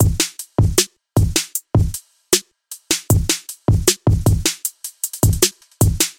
描述：A set of DrumBass/Hardcore loops (more DnB than Hardcore) and the corresponding breakbeat version, all the sounds made with milkytracker.
标签： 155bpm bass break drum hardcore
声道立体声